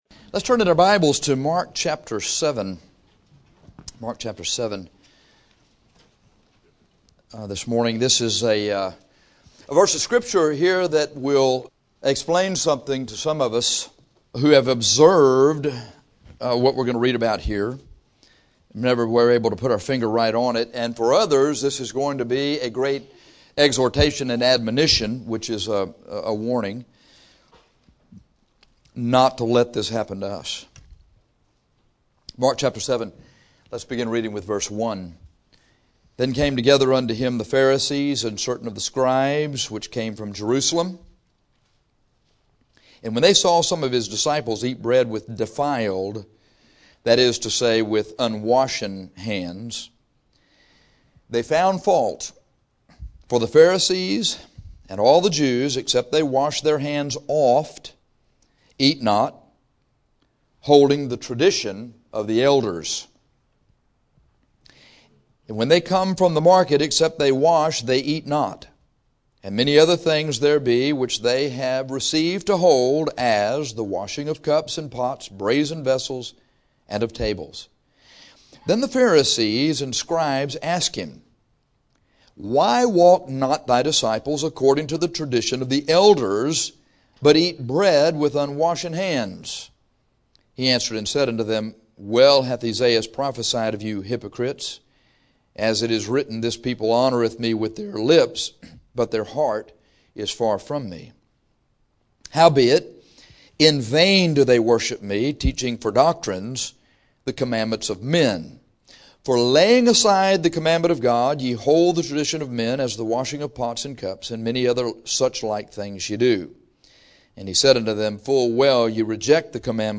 Conclusion : v.13 – when your heart is far from God, the word of God has no effect on you – sadly there are some here today whose hearts are so far from God that this sermon has bounced off your head without reaching your mind or your heart.